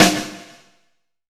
SNARE 023.wav